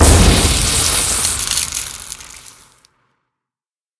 assets / minecraft / sounds / mob / zombie / remedy.ogg